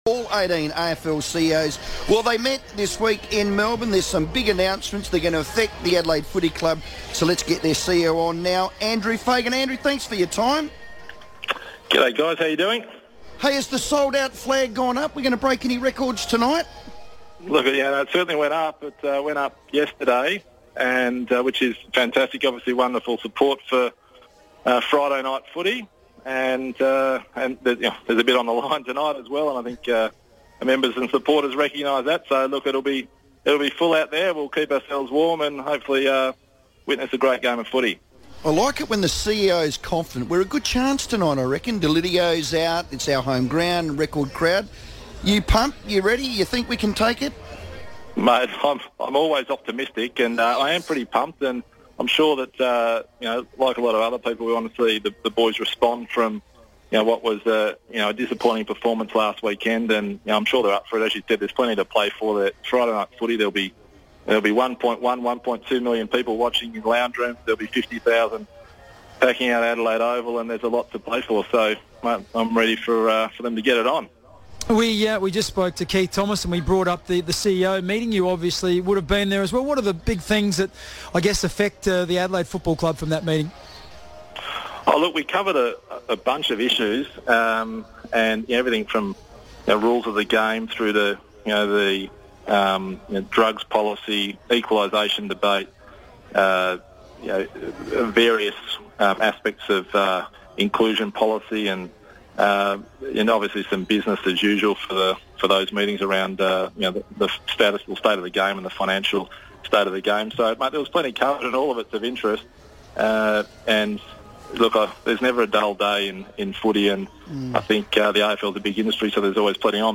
FIVEaa radio